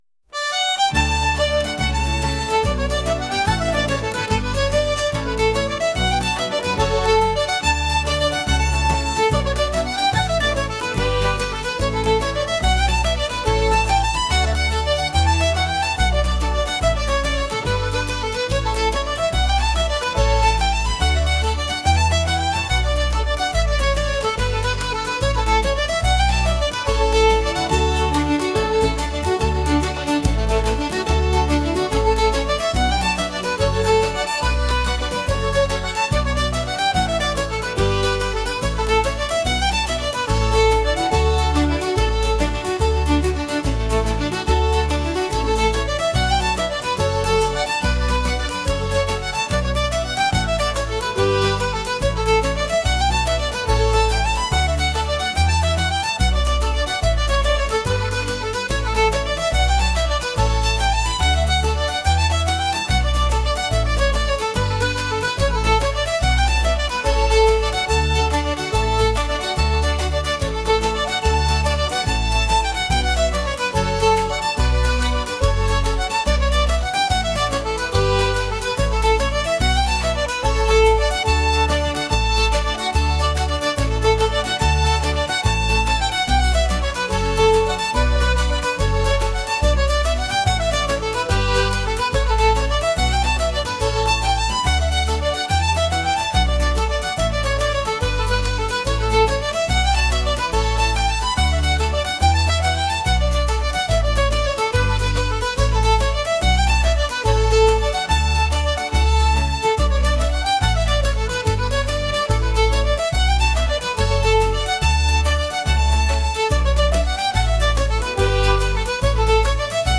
folk | traditional